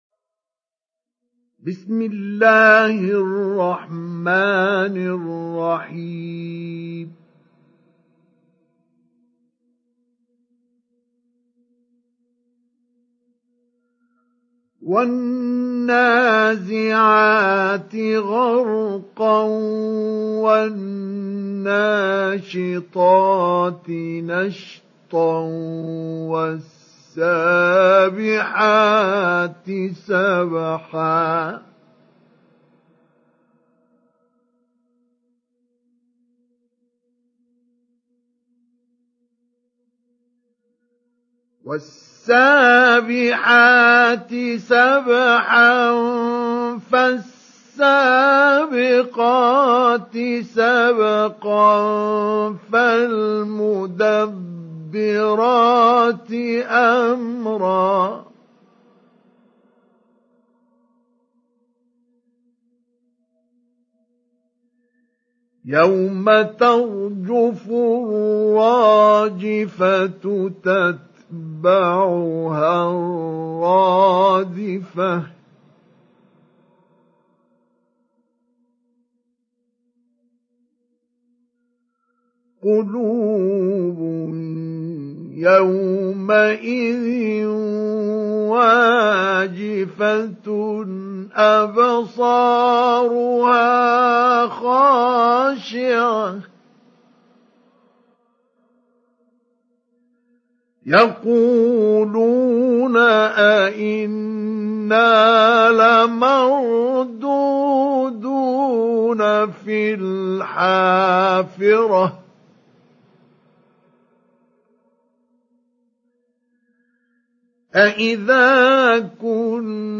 Download Surat An Naziat Mustafa Ismail Mujawwad